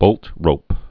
(bōltrōp)